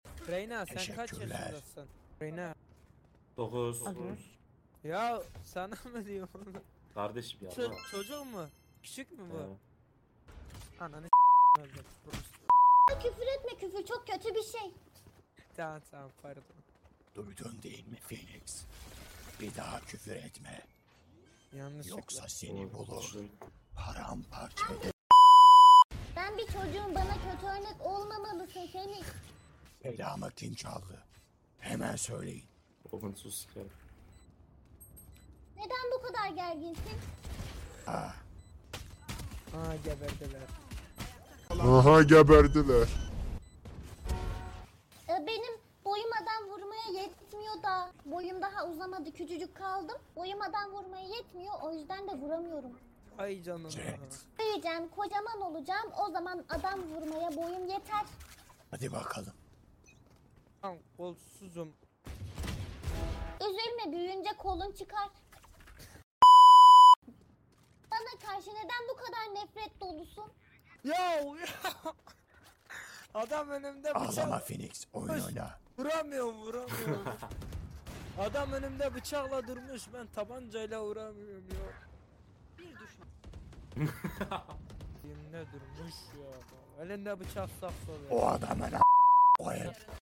Bugün sizlere Mp3 Sound Effect Merhaba Valorant tutkunları! Bugün sizlere Valorant'ta yaptığım trolleri sesimle paylaşıyorum. Keyifli ve komik anlar için videomu izlemeyi unutmayın!